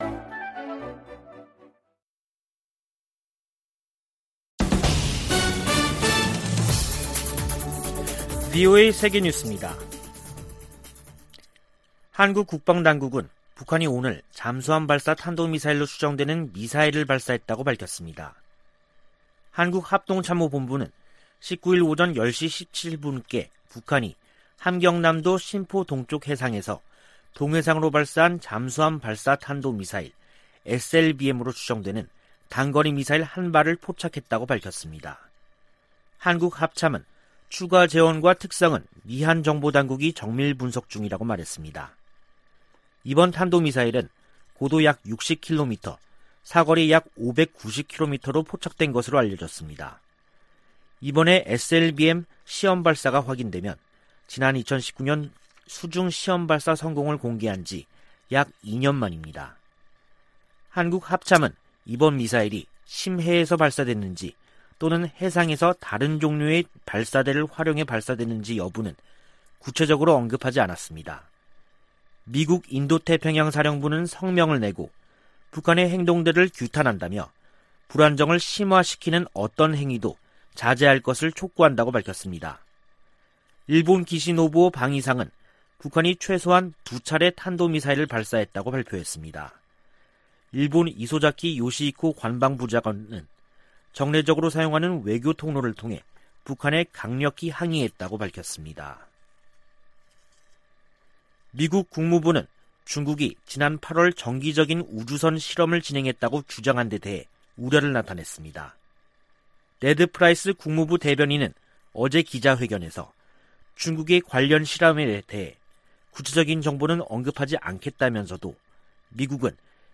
VOA 한국어 간판 뉴스 프로그램 '뉴스 투데이', 2021년 10월 19일 2부 방송입니다. 한국 합동참모본부는 북한이 동해상으로 잠수함발사 탄도미사일(SLBM)로 추정되는 단거리 미사일 1발을 발사했음을 포착했다고 밝혔습니다. 미국과 한국, 일본 정보수장이 서울에서 만나 단거리 미사일 발사 등 북한 문제를 협의했습니다. 성 김 미 대북특별대표는 한국 정부가 제안한 종전선언에 관해 계속 논의할 것이라고 밝혔습니다.